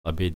prononciation
abed_prononciation02.mp3